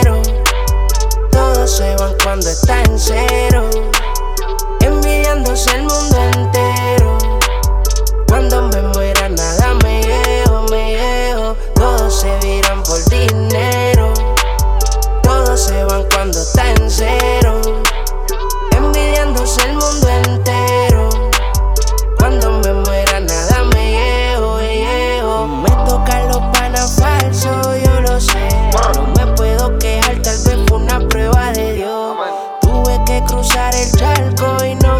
Urbano latino Latin
Жанр: Латино